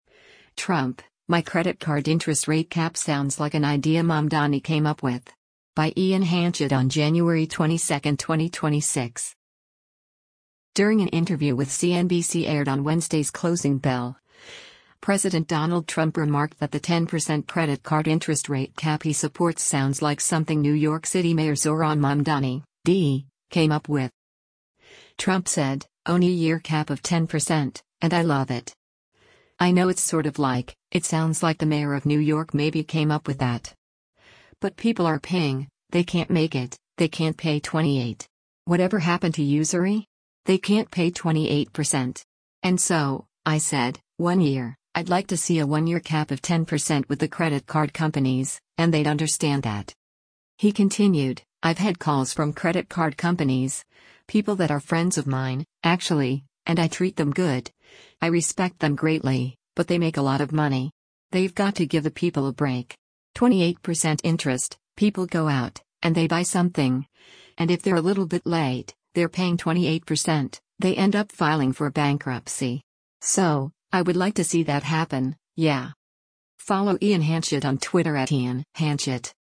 During an interview with CNBC aired on Wednesday’s “Closing Bell,” President Donald Trump remarked that the 10% credit card interest rate cap he supports “sounds like” something New York City Mayor Zohran Mamdani (D) came up with.